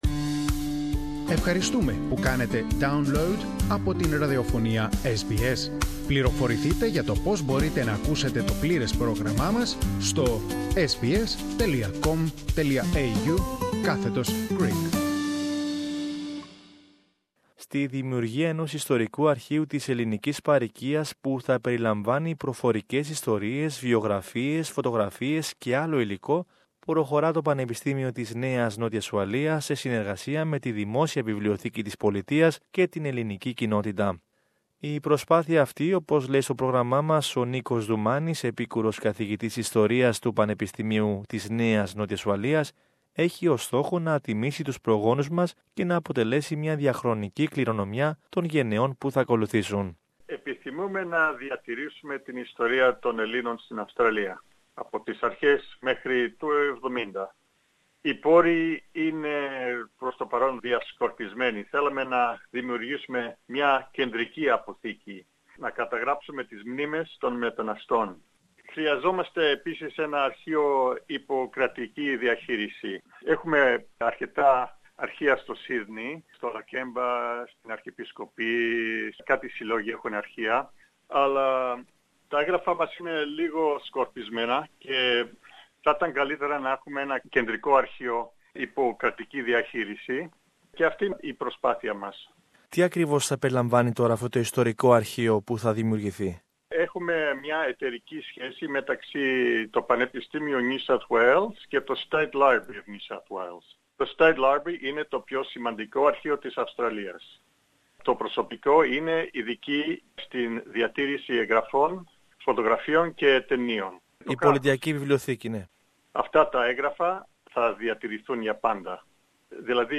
Περισσότερα ακούμε στην συνέντευξη που ακολουθεί.